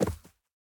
Minecraft Version Minecraft Version snapshot Latest Release | Latest Snapshot snapshot / assets / minecraft / sounds / mob / armadillo / unroll_finish2.ogg Compare With Compare With Latest Release | Latest Snapshot
unroll_finish2.ogg